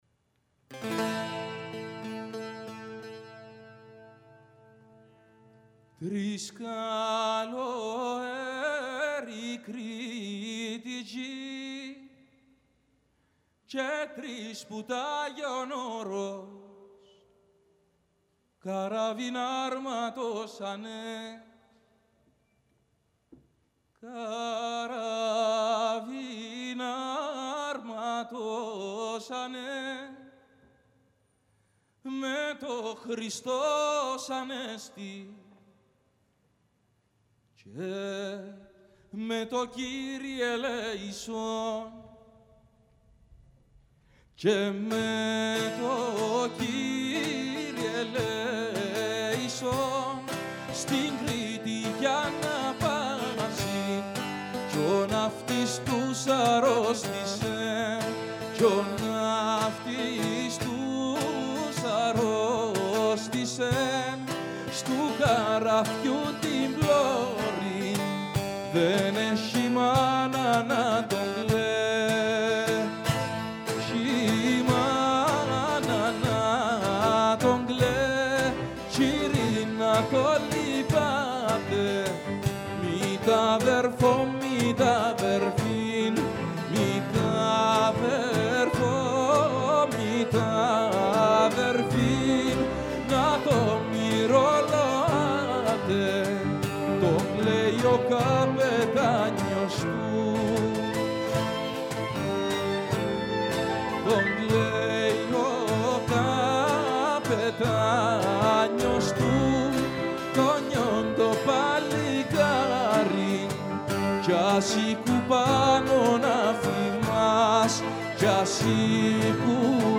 “Τρεις Καλοήροι” – Παραδοσιακό Κύπρου
για Φωνή και Ορχήστρα (live)
Ορχήστρα Φεστιβάλ Πάτμου